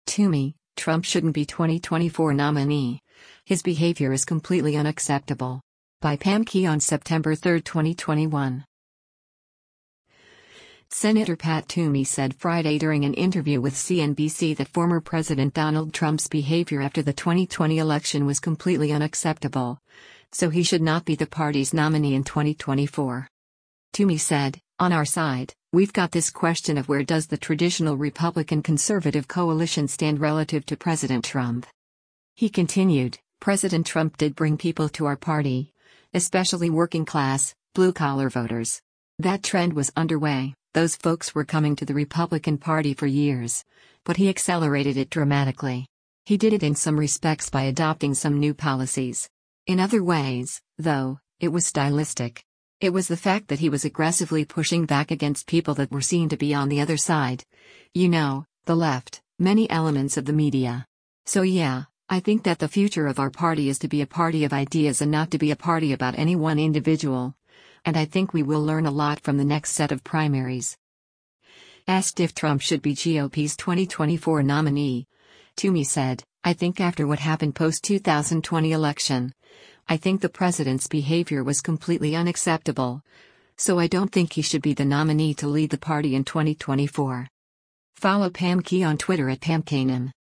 Senator Pat Toomey said Friday during an interview with CNBC that former president Donald Trump’s behavior after the 2020 election was “completely unacceptable,” so he should not be the party’s nominee in 2024.